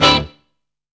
GIT ROCKIN.wav